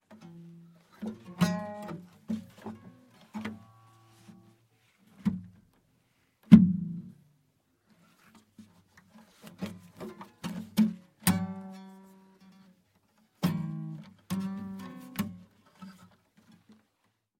Звуки гитары, струн
Звук касания пальцами струн гитары для монтажа